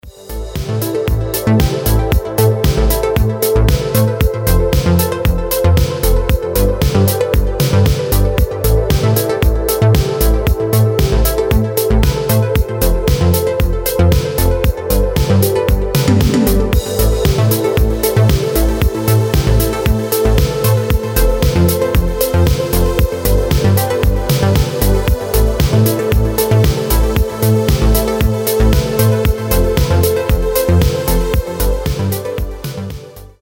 Без слов Deep house Nu disco Мелодичные
Танцевальные